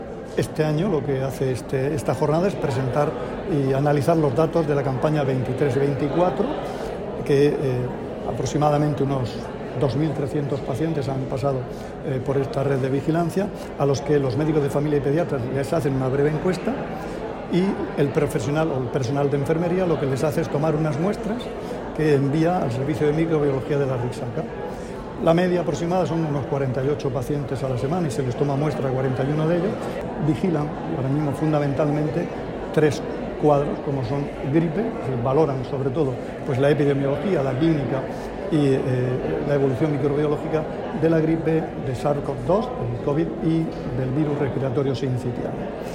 Declaraciones del consejero de Salud, Juan José Pedreño, sobre la labor de la Red Centinela de la Región de Murcia